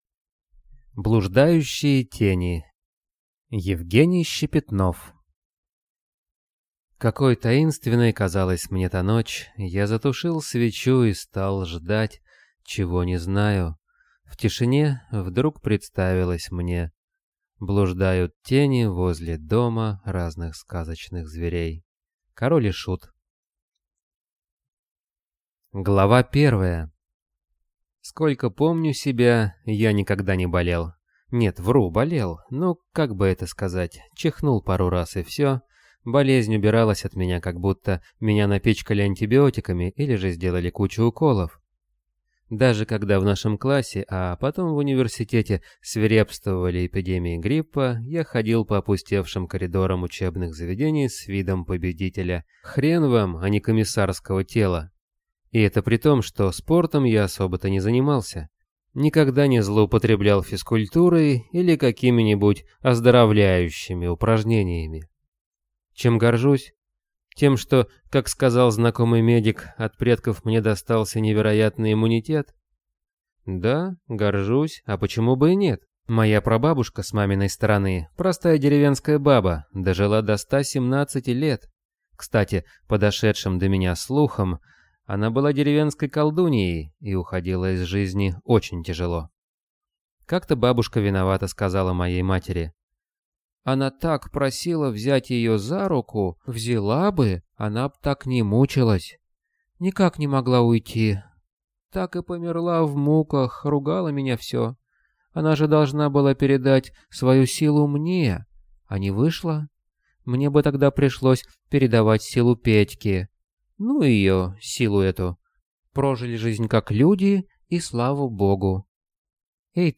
Аудиокнига Блуждающие тени | Библиотека аудиокниг